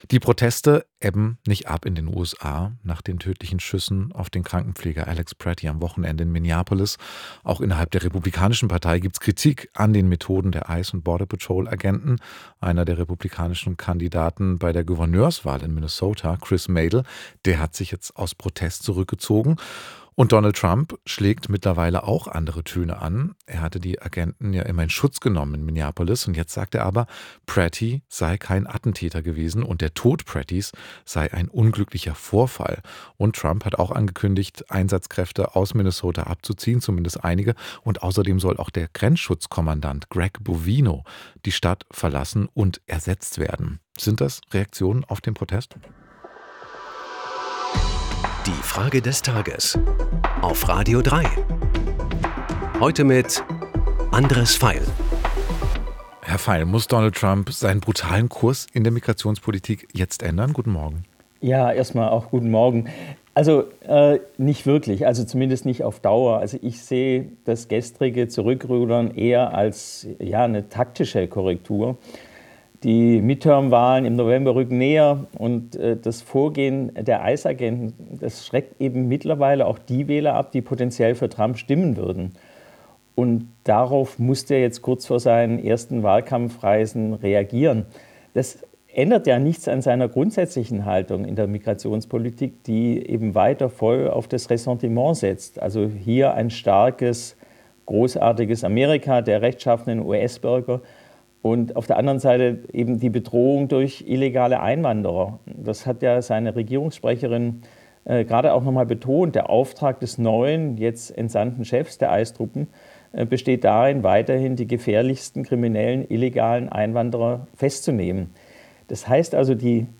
Es antwortet der Filmemacher Andres Veiel.